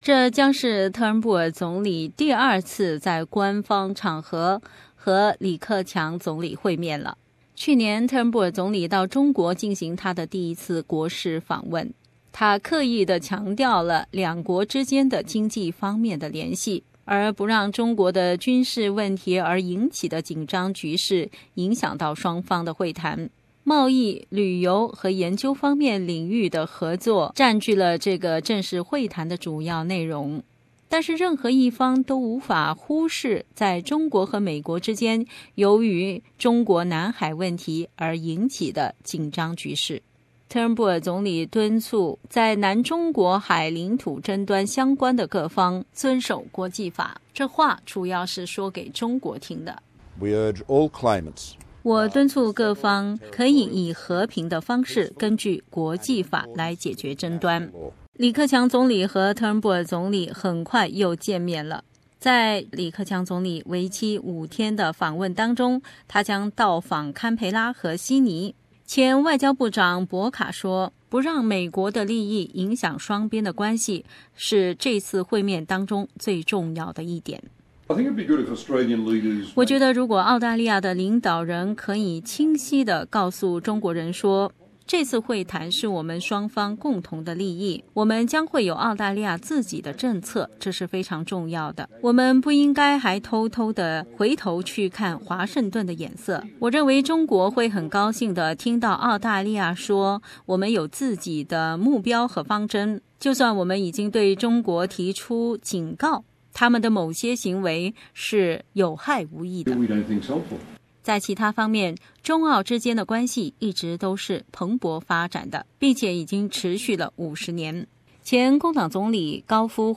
点击以上播放条听本台的综合报道。